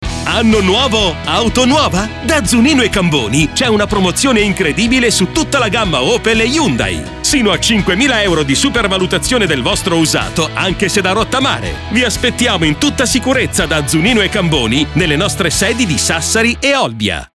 Spot promozione usato